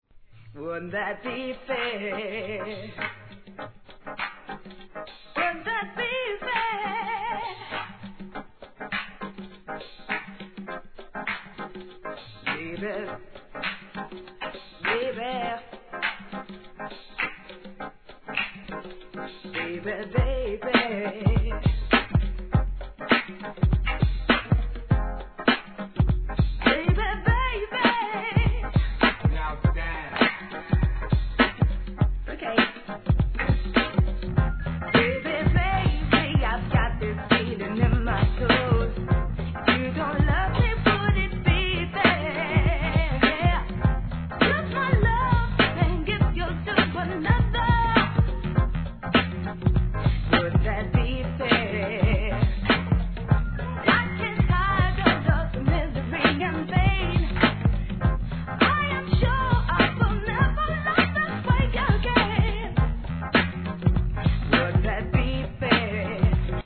HIP HOP/R&B
JAZZ, SOUL, REGGAE...と様々な要素を見事に取り入れ